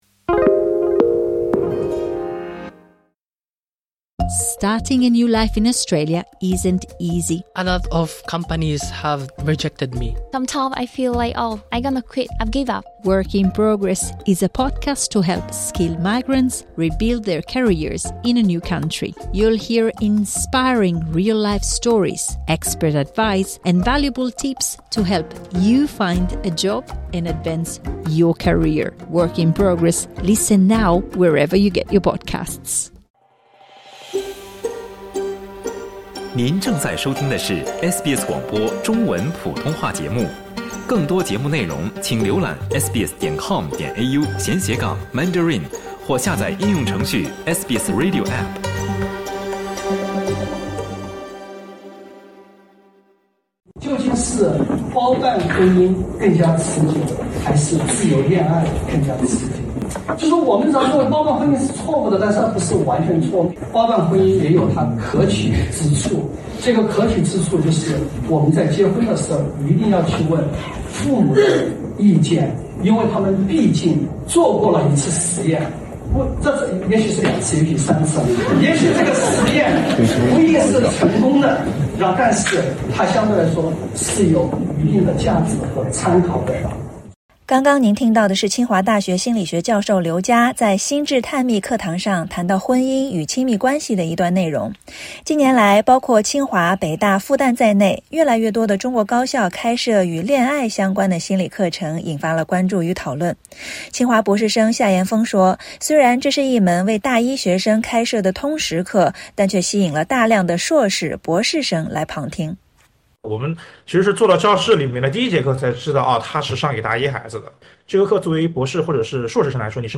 点击音频收听报道